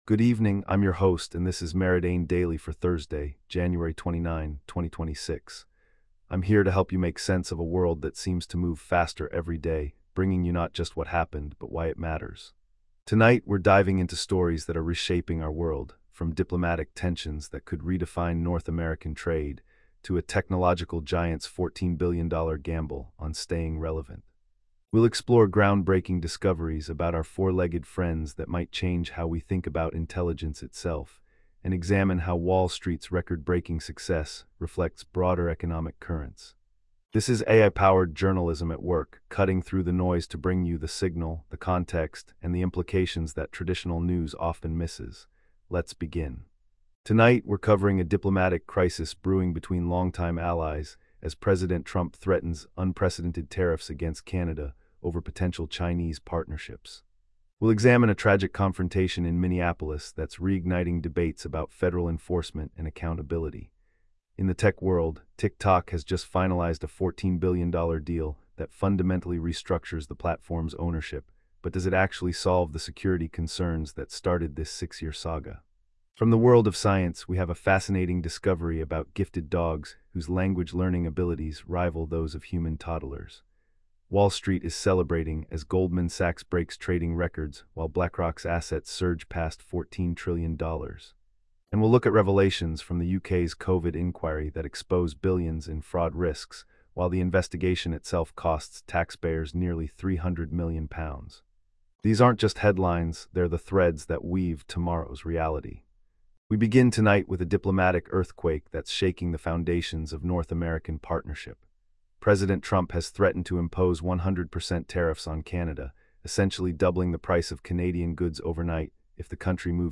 Your nightly AI-powered news briefing for Jan 29, 2026